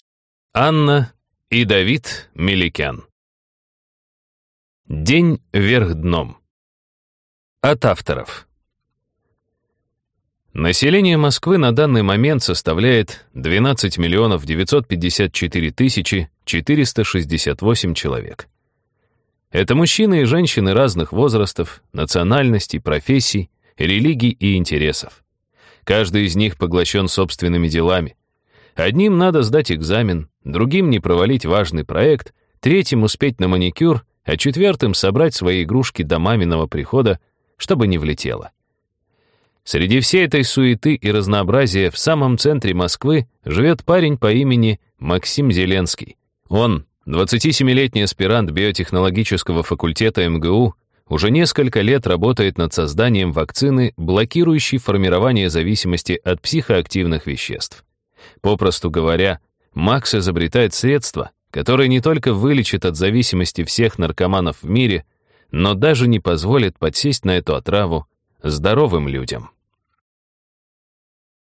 Аудиокнига День вверх дном | Библиотека аудиокниг